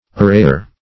Search Result for " arrayer" : The Collaborative International Dictionary of English v.0.48: Arrayer \Ar*ray"er\, n. One who arrays.